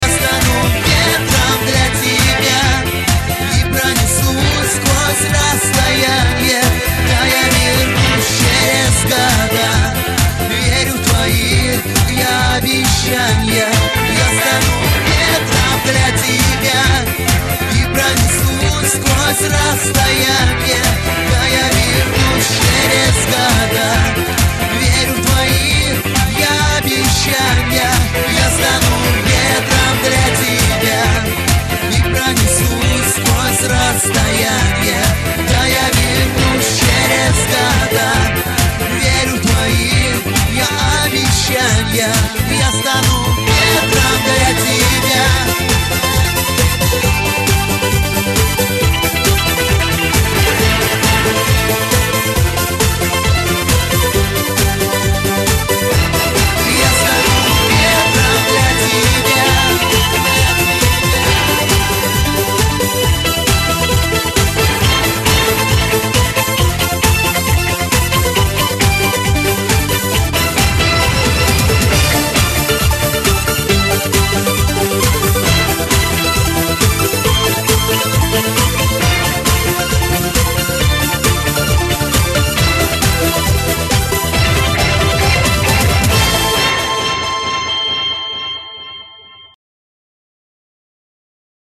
• Качество: 320, Stereo
громкие
русский шансон
танцевальные
эстрадные